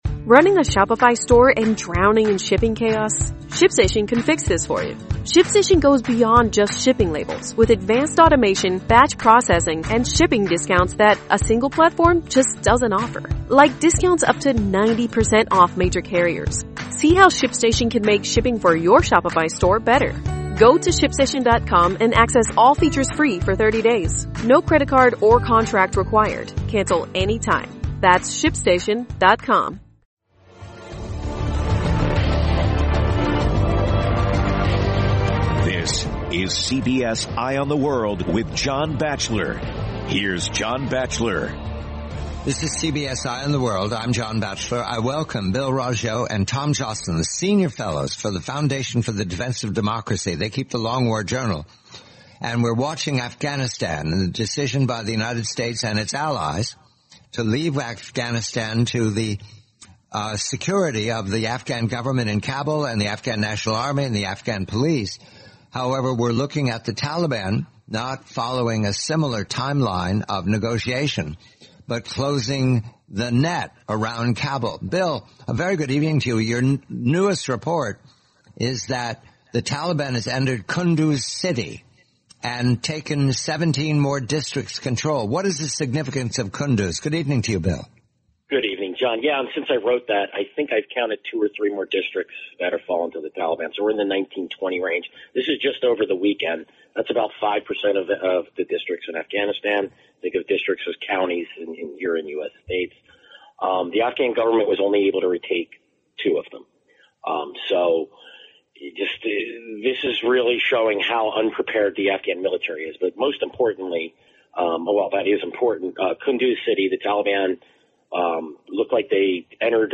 The complete, nineteen minute-interview, June 21, 2021.